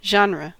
Ääntäminen
IPA : /ˈ(d)ʒɒnɹə/ US